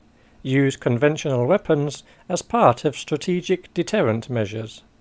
DICTATION 6